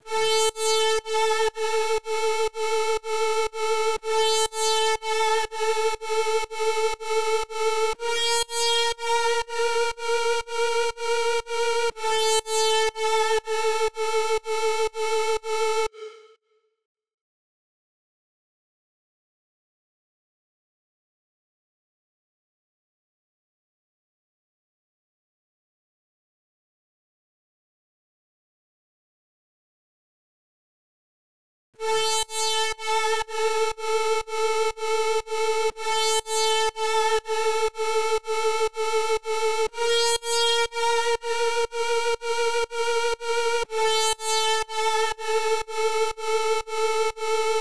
🔹 50 Premium Serum Presets crafted for melodic house, cinematic soundscapes, and deep emotional productions.
• Layered & Textured Sounds for that big cinematic feel
Preset Preview
RearView-Lights-0014-Instrument-LD-Static-Halo.wav